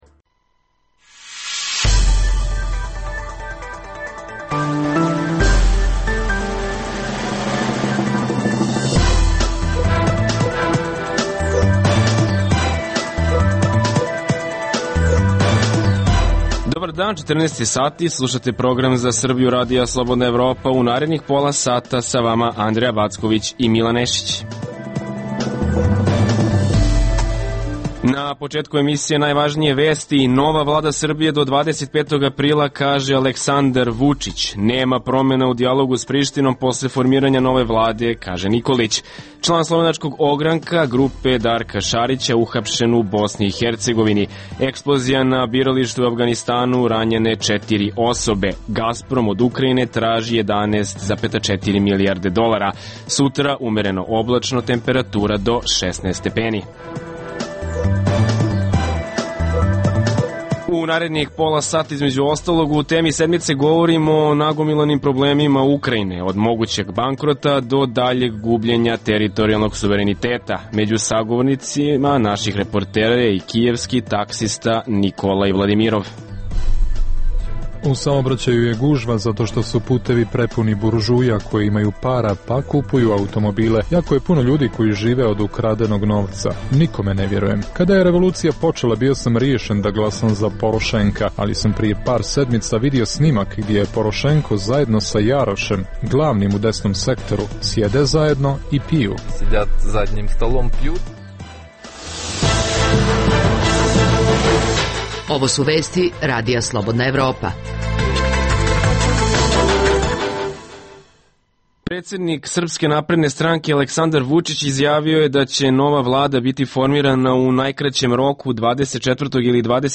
U temi sedmice govorimo o nagomilanim problemima problemima Ukrajine: od mogućeg bankrota, do daljeg gubljenja teritorijalnog suvereniteta. Među sagovornicima naših reportera je i jedan kijevski taksista.